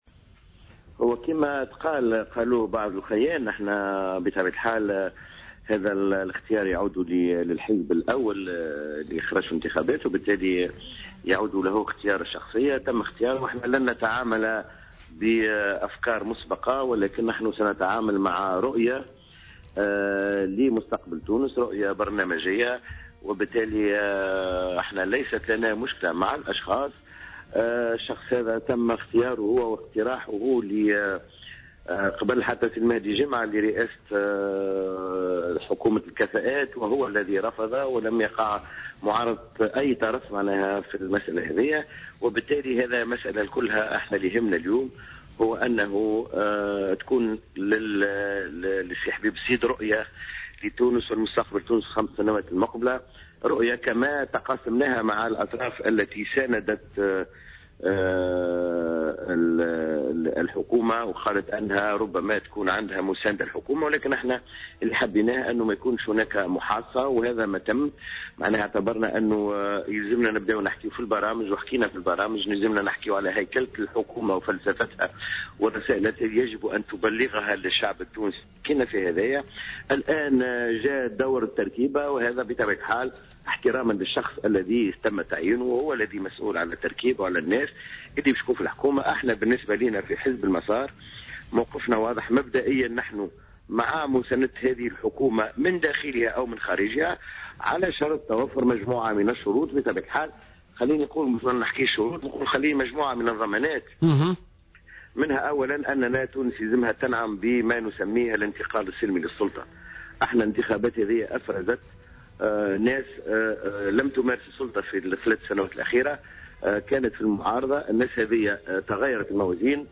Samir Bettaieb, leader au parti Al Massar, est intervenu sur les ondes de Jawhara FM ce mardi 6 janvier 2015 dans le cadre de l’émission Politica.